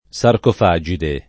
[ S arkof #J ide ]